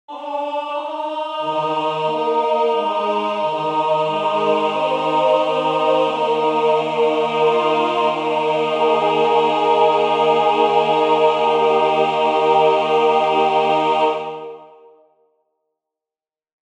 Key written in: E♭ Major
How many parts: 4
Type: Barbershop
Comments: All parts track is just synth not actual voices.
All Parts mix: